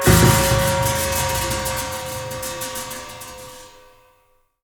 A#3 RATTL0OR.wav